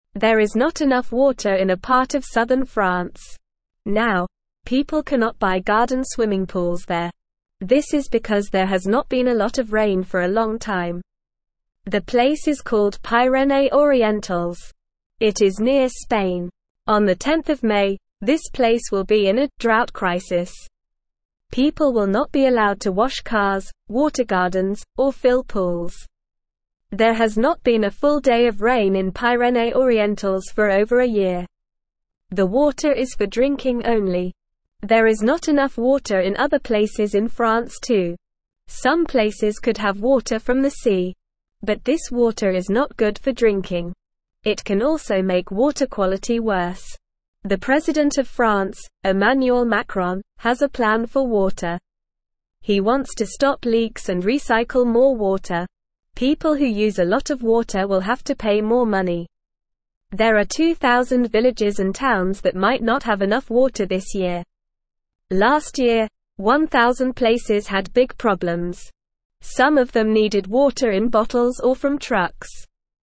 Normal
English-Newsroom-Beginner-NORMAL-Reading-No-Swimming-Pools-in-Dry-French-Area.mp3